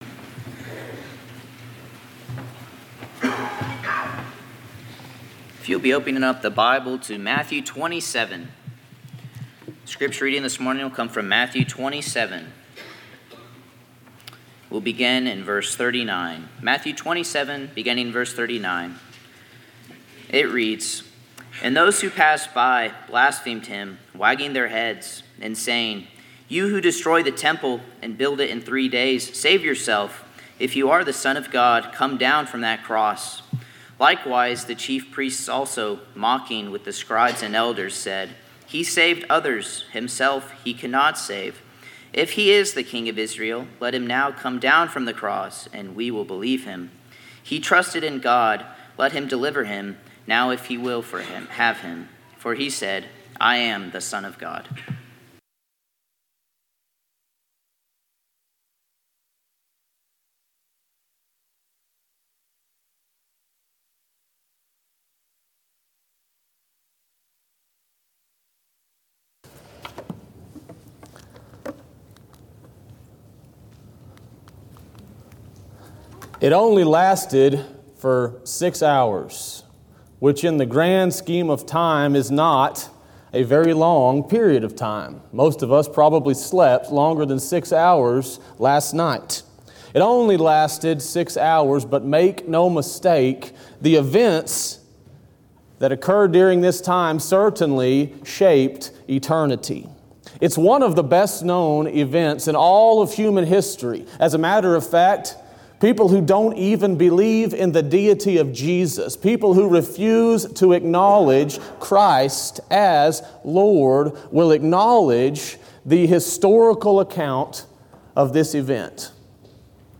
Sermons by Westside Church of Christ